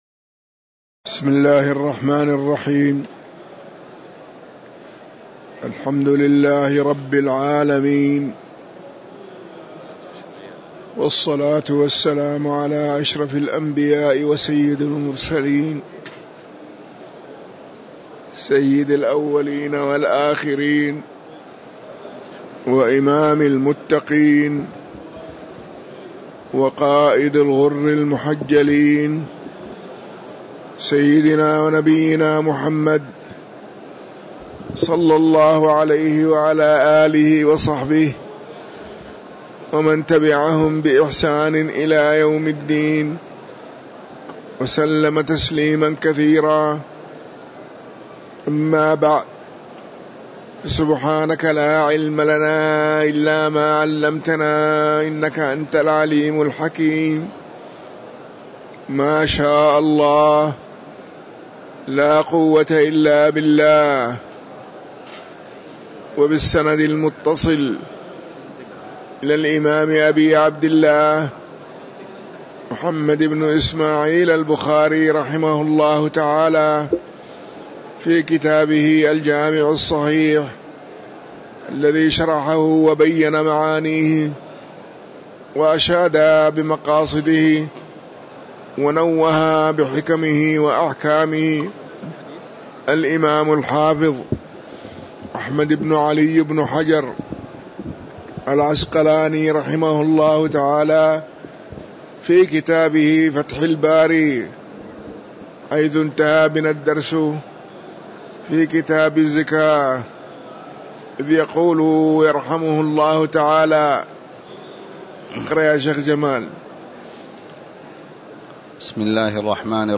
تاريخ النشر ١٤ ربيع الثاني ١٤٤٤ هـ المكان: المسجد النبوي الشيخ